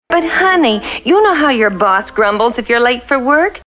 Boss grumbles
Category: Television   Right: Personal
Tags: Wilma Flintstone Wilma Flintstone clips Wilma Flintstone sounds The Flinstones Cartoon